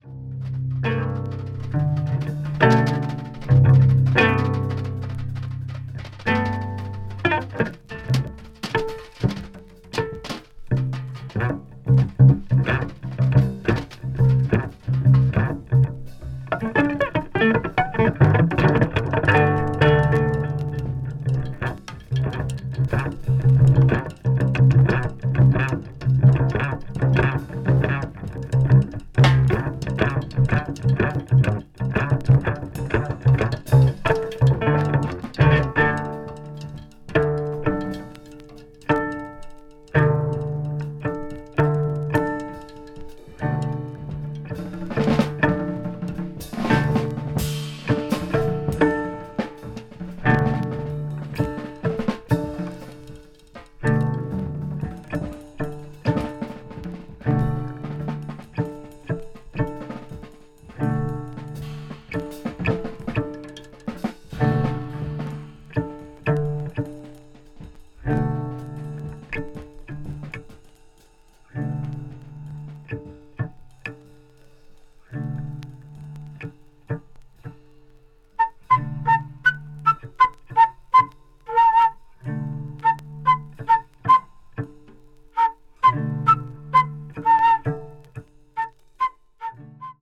avant-jazz   ethnic jazz   free improvisation   free jazz